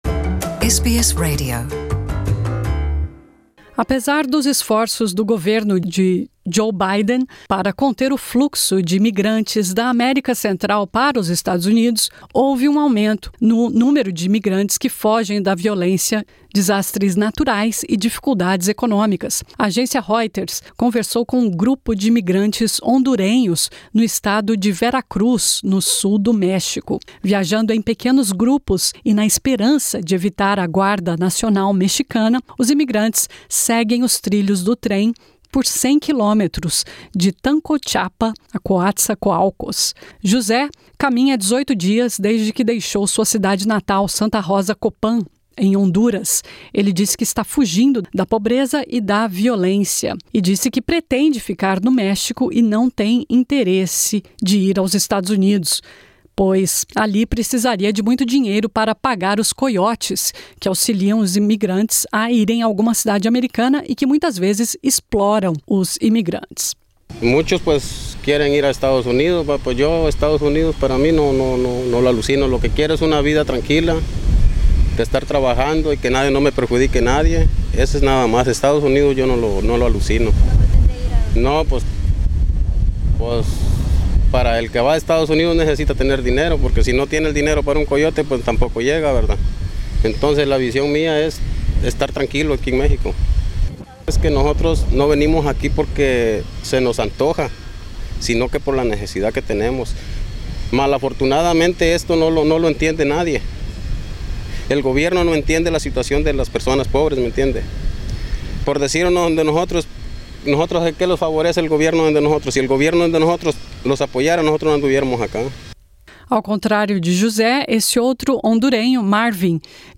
O presidente Joe Biden tem sido criticado por sua política de migração que manteve a separação de famílias, que ocorre agora do lado mexicano. Confira aqui relatos emocionados dos hondurenhos que chegam aos milhares todos os dias na fronteira em busca de uma vida melhor em solo americano.